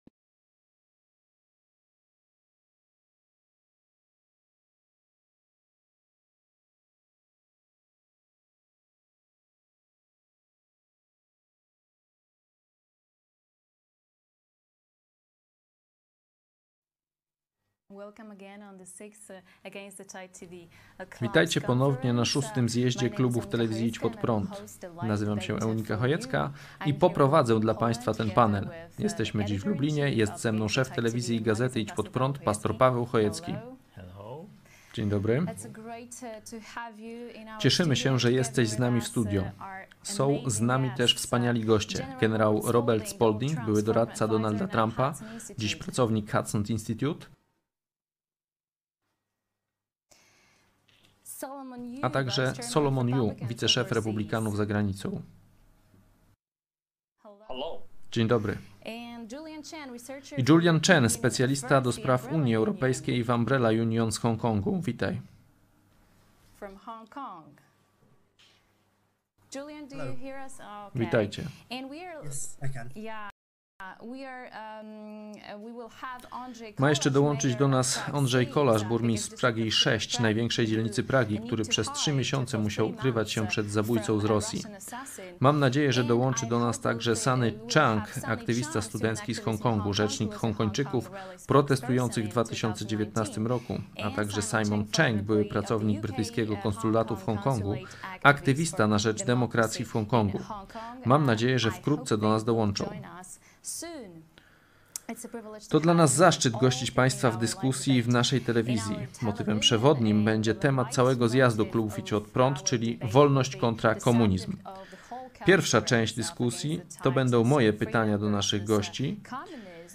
DEBATA "Wolność vs komunizm": Czy Chiny odbiorą nam wolność?
Międzynarodowa DEBATA "Wolność vs komunizm": Czy Chiny odbiorą nam wolność?